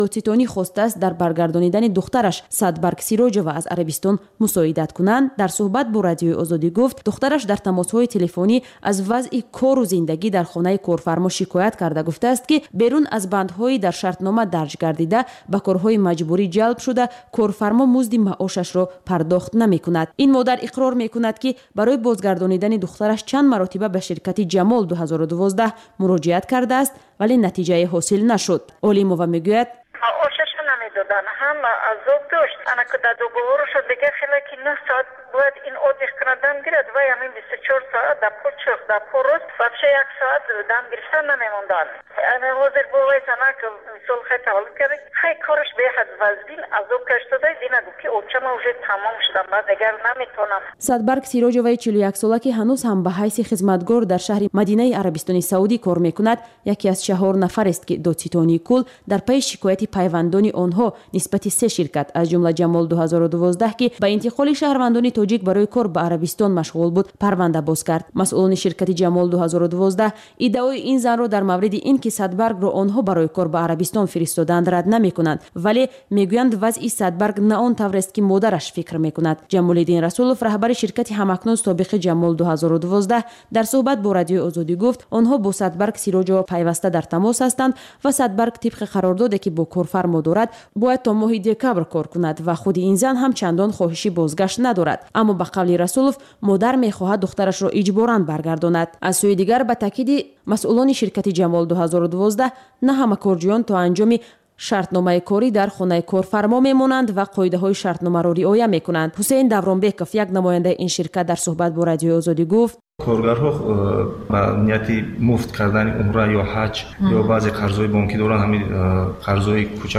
Гуфтори вижаи Радиои Озодӣ аз ҳаёти ҷавонони Тоҷикистон ва хориҷ аз он. Дар ин барнома таҳаввулоти ҷавонон аз дидгоҳҳои сиёсиву иҷтимоӣ, фарҳанги маданӣ тақдим мешавад